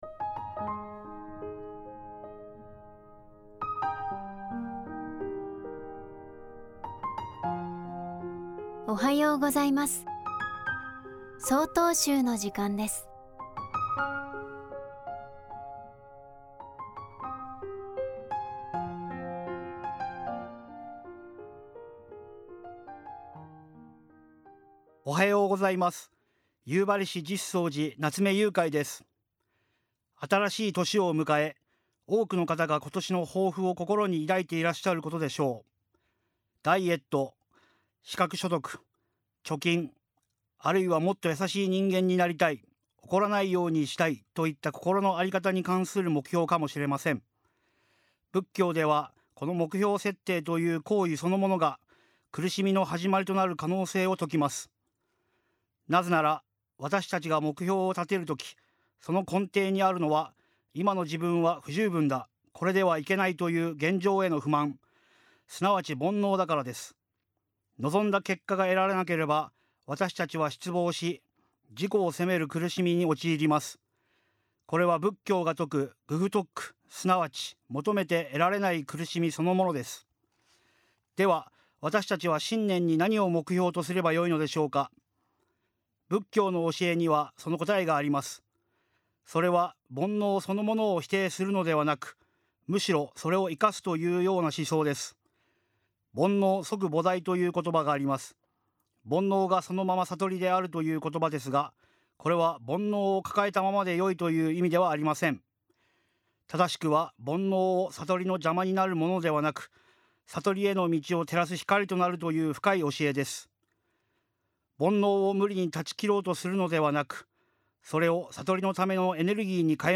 法話